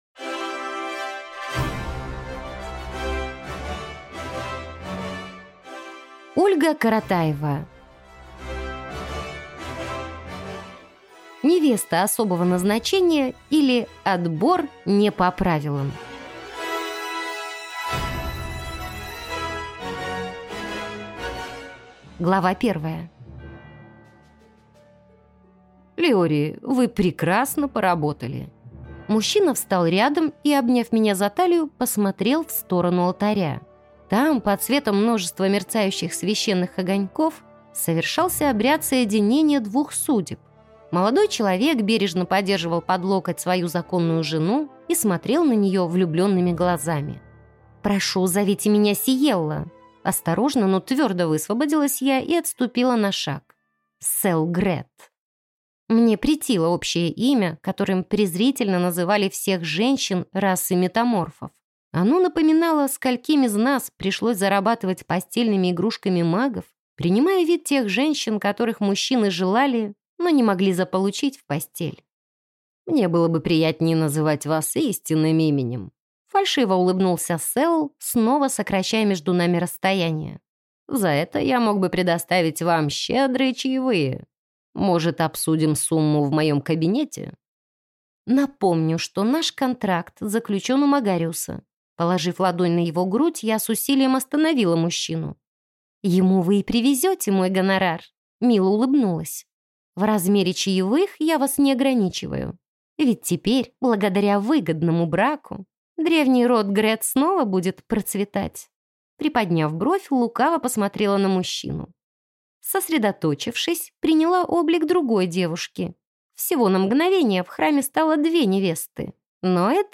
Аудиокнига Невеста особого назначения, или Отбор не по правилам | Библиотека аудиокниг
Прослушать и бесплатно скачать фрагмент аудиокниги